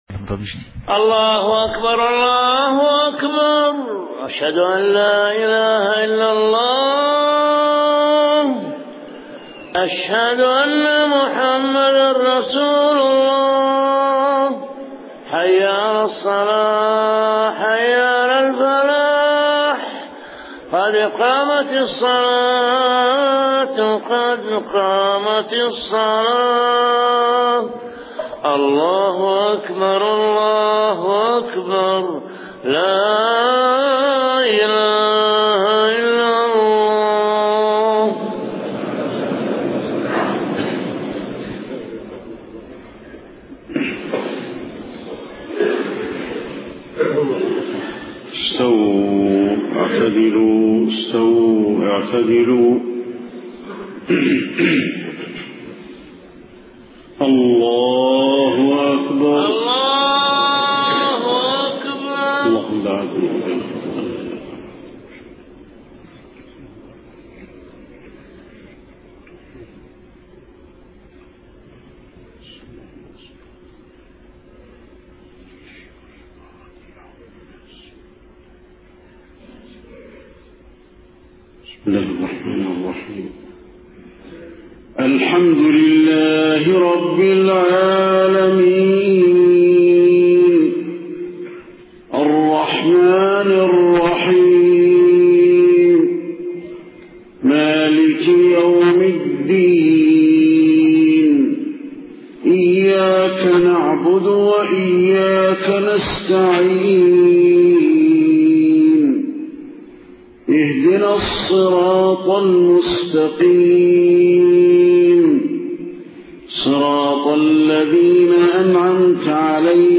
صلاة الفجر 11 ربيع الأول 1431هـ فواتح سورة الواقعة 1-74 > 1431 🕌 > الفروض - تلاوات الحرمين